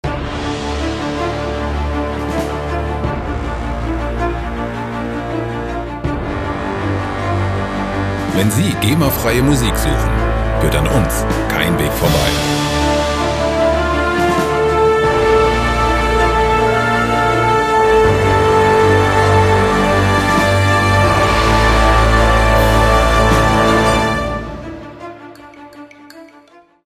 epische Musikloops lizenzfrei
Musikstil: Action Musik
Tempo: 80 bpm